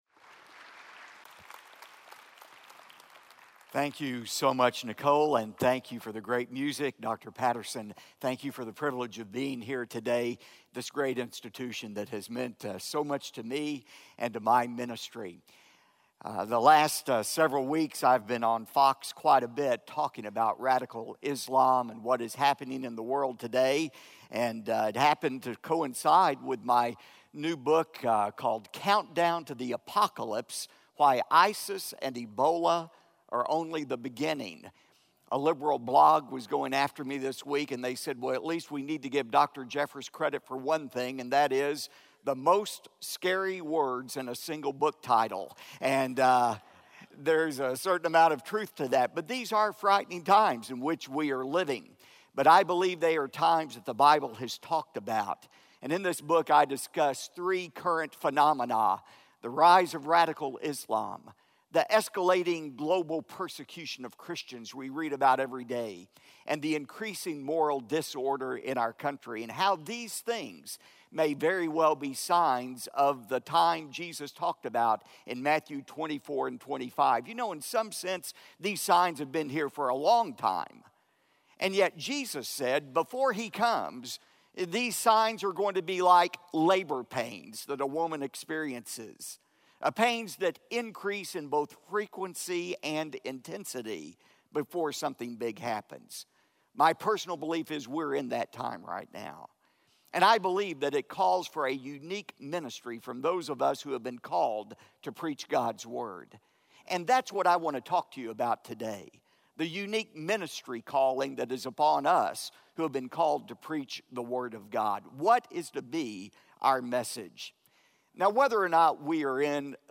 Dr. Robert Jeffress speaking on Matthew 5:13 in SWBTS Chapel on Thursday February 26, 2015
SWBTS Chapel Sermons Robert Jeffress - Three Explosive Decisions Play Episode Pause Episode Mute/Unmute Episode Rewind 10 Seconds 1x Fast Forward 30 seconds 00:00 / Subscribe Share RSS Feed Share Link Embed